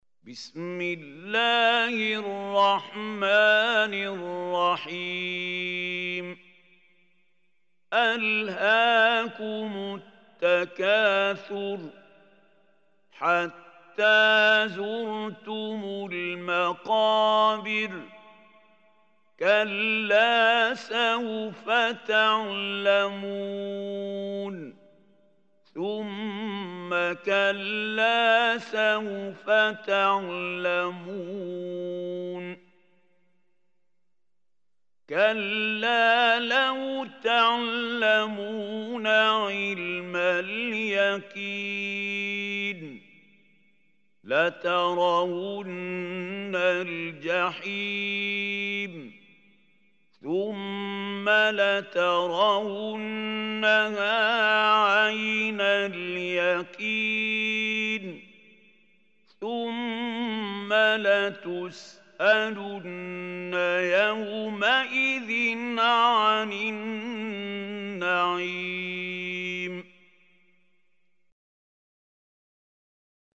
Surah Takathur MP3 Recitation by Khalil Hussary
Surah Takathur, is 102 surah of Holy Quran. Listen or play online mp3 tilawat / recitation in the beautiful voice of Sheikh Mahmoud Khalil Hussary.